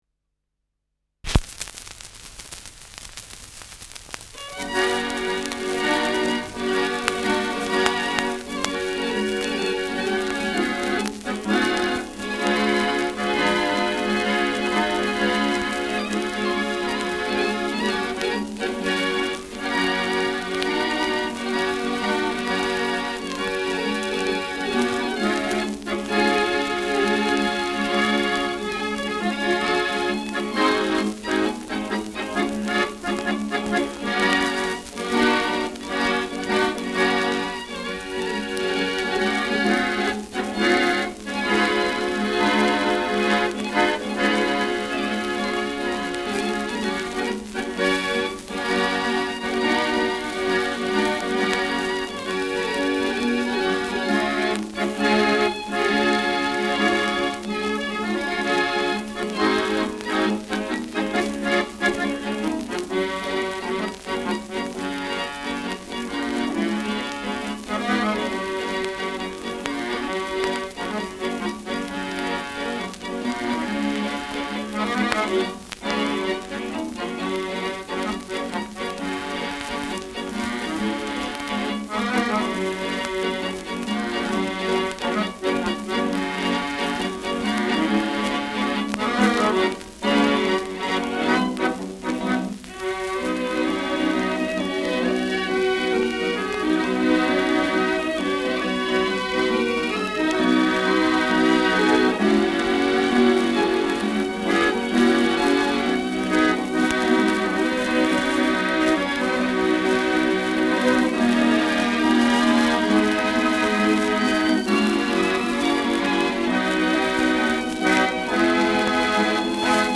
Schellackplatte
leichtes Knistern